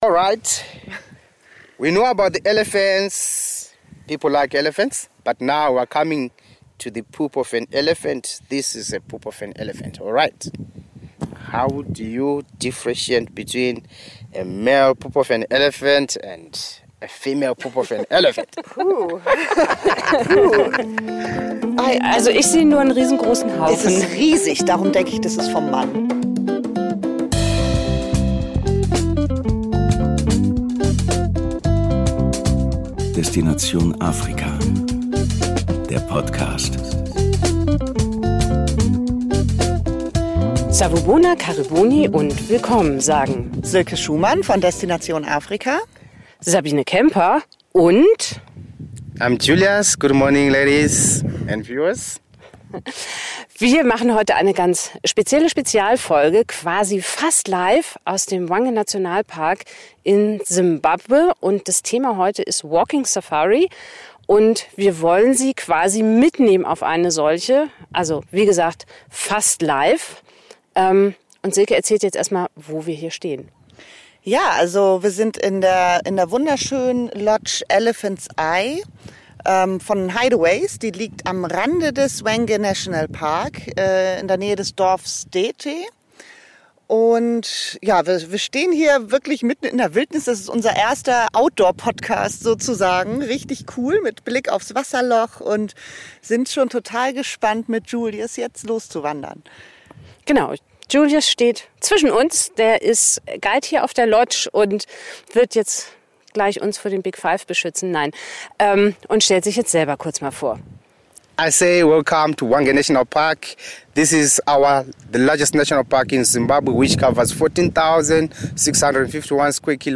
Ohne Motorlärm, ohne Blech-Barriere und ohne Umweg: Wir nehmen Sie (fast) live mit auf eine Safari per Pedes in Zimbabwe.